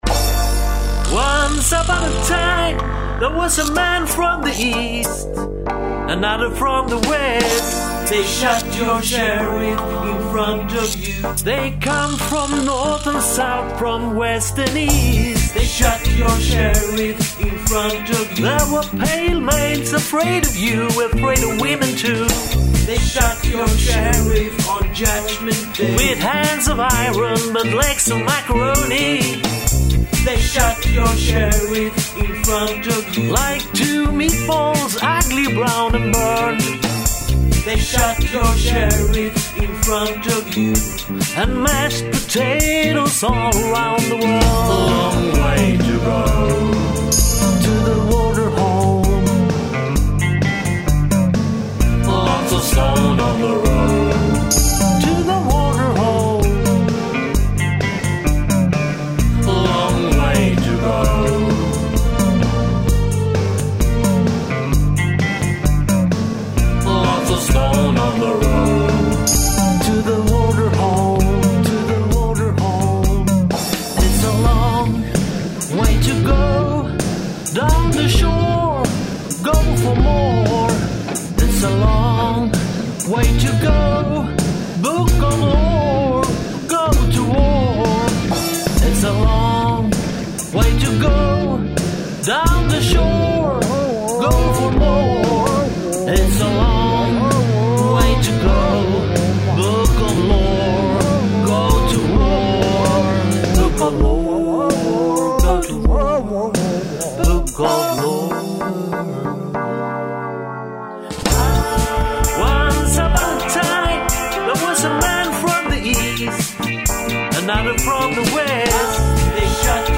All songs are recorded and mixed in their home studios.